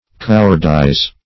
Search Result for " cowardize" : The Collaborative International Dictionary of English v.0.48: Cowardize \Cow"ard*ize\ (-?z), v. t. To render cowardly.
cowardize.mp3